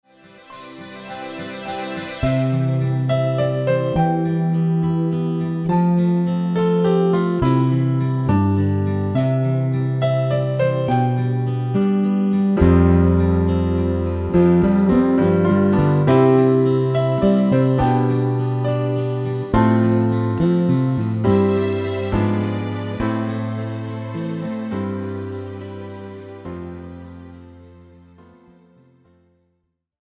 KARAOKE/FORMÁT:
Žánr: Pohádková
BPM: 104
Key: C